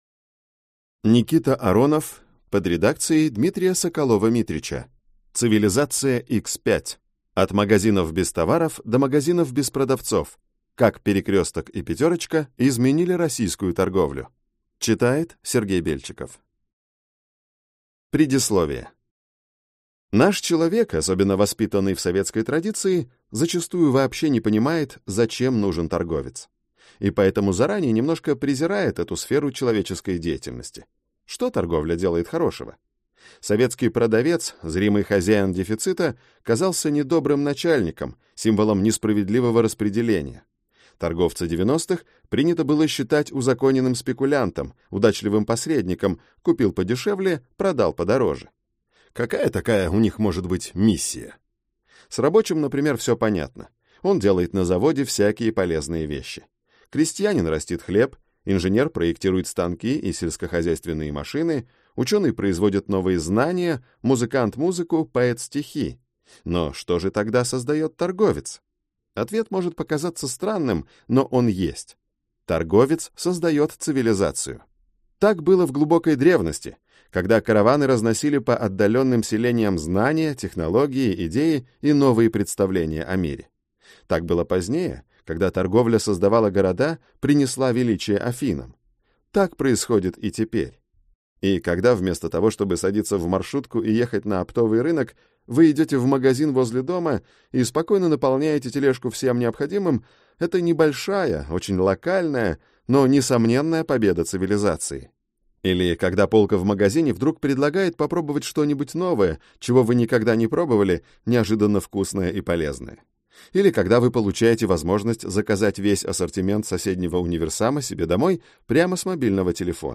Аудиокнига Цивилизация X5. От магазинов без товаров до магазинов без продавцов. Как «Перекресток» и «Пятерочка» изменили российскую торговлю | Библиотека аудиокниг